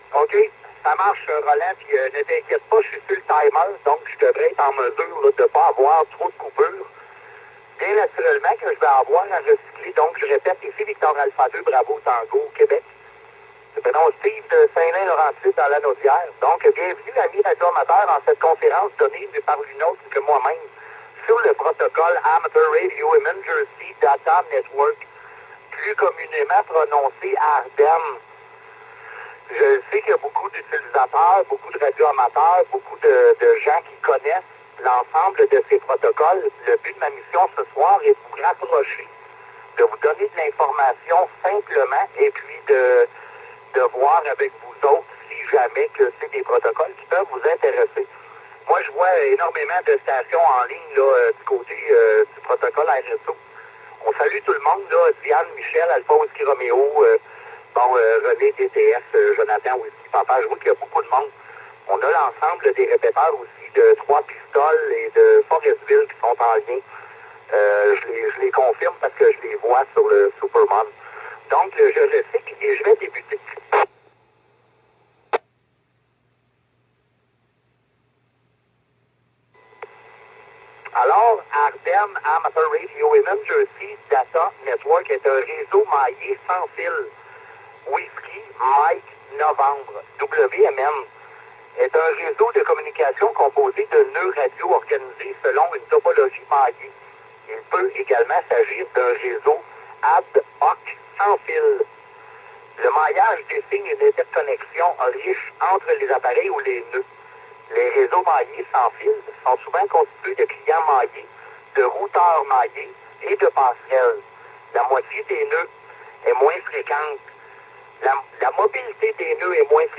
Conférence ARED net
conference_aredn.mp3